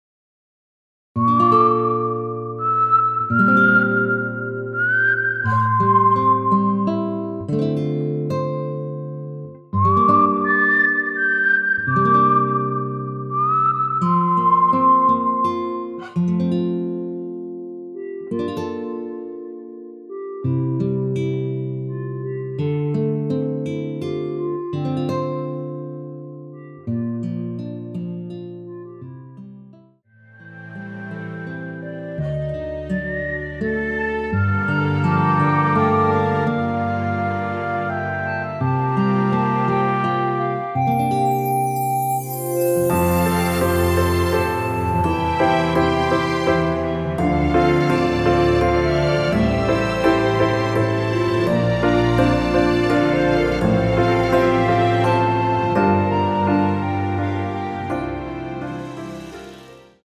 무반주 구간 들어가는 부분과 박자 맞출수 있게 쉐이커로 박자 넣어 놓았습니다.(일반 MR 미리듣기 참조)
앞부분30초, 뒷부분30초씩 편집해서 올려 드리고 있습니다.
중간에 음이 끈어지고 다시 나오는 이유는